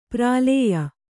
♪ prālēya